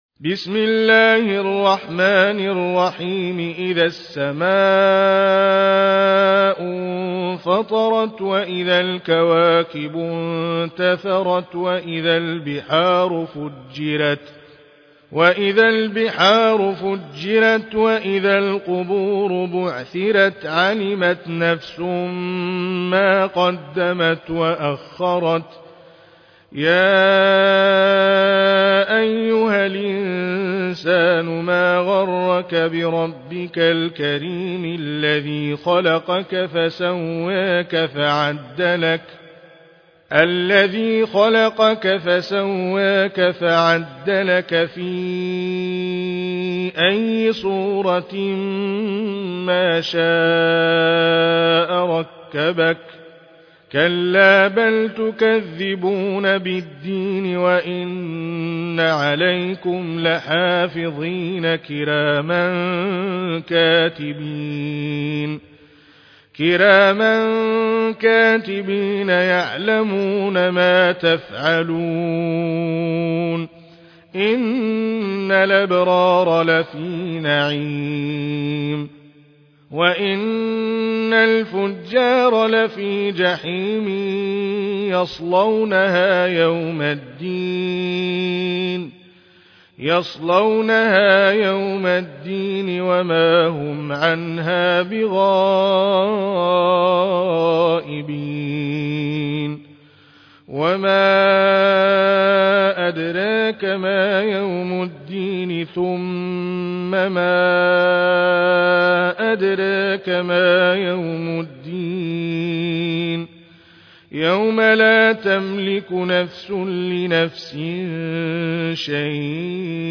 Récitation